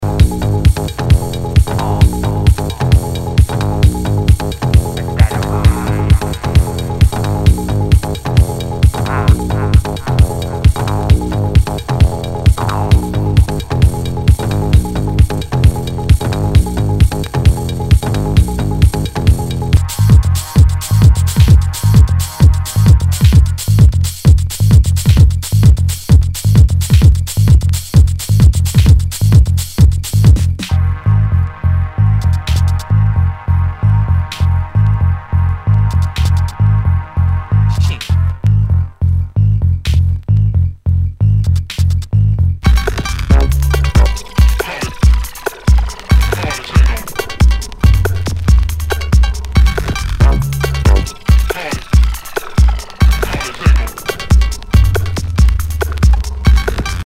HOUSE/TECHNO/ELECTRO
ナイス！テック・ハウス！
全体にチリノイズが入ります